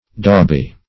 dauby - definition of dauby - synonyms, pronunciation, spelling from Free Dictionary Search Result for " dauby" : The Collaborative International Dictionary of English v.0.48: Dauby \Daub"y\, a. Smeary; viscous; glutinous; adhesive.